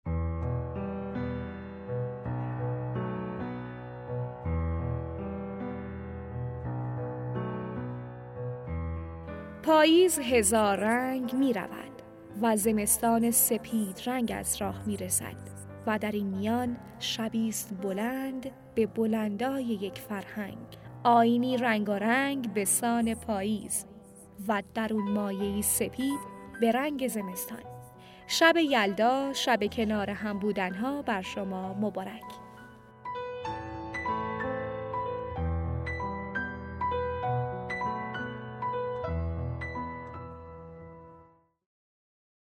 تبریک شب یلدا به رفیق